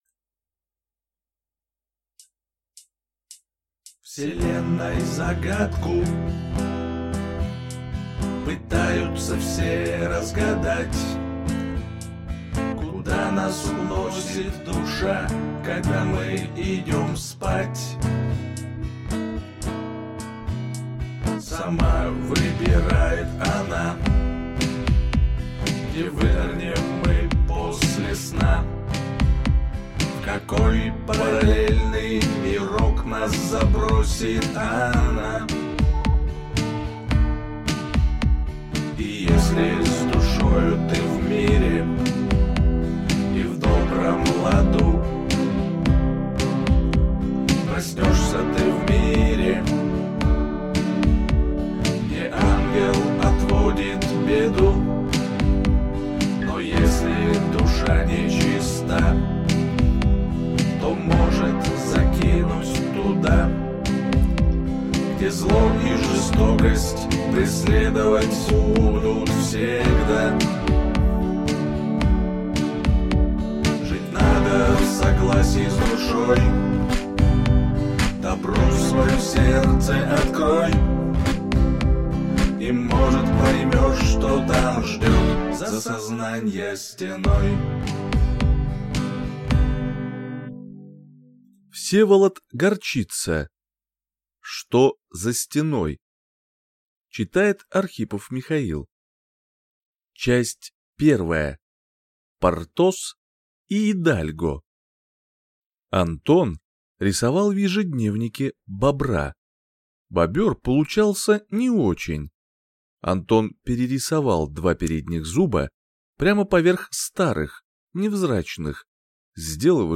Aудиокнига Что за стеной?